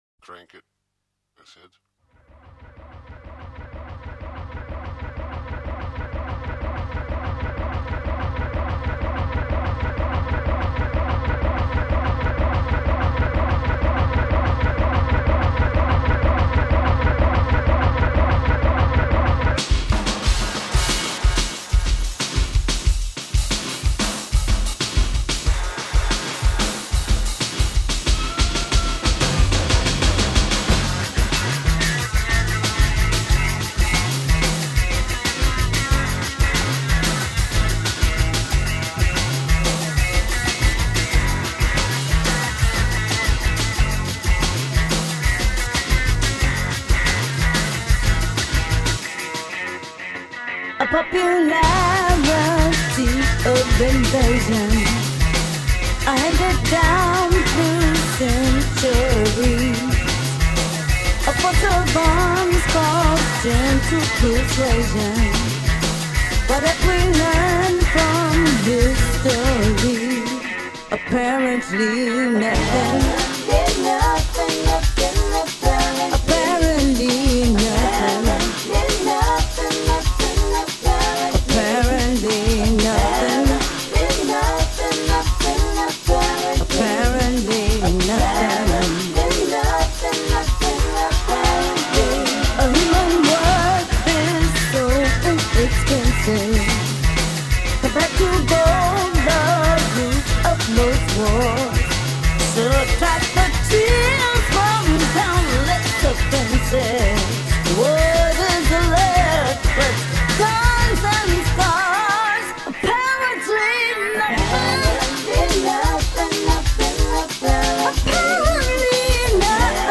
This one is lower and more soulful.